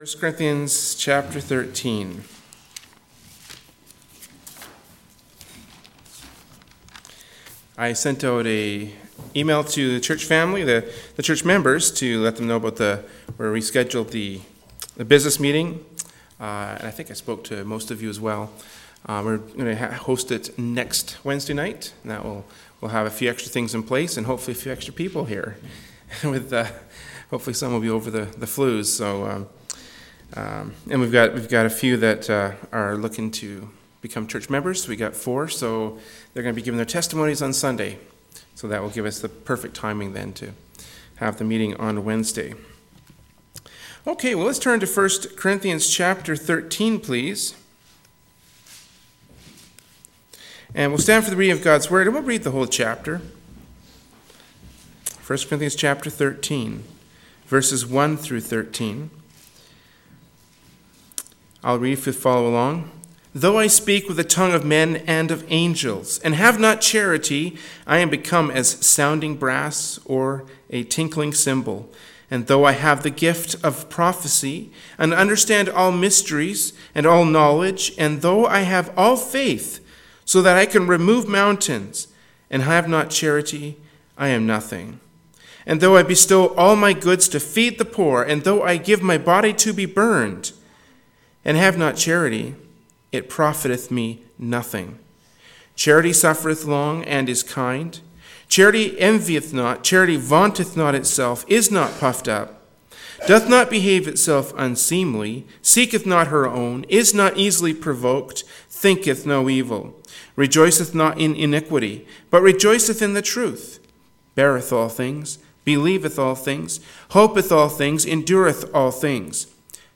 Service Type: Wednesday Evening Service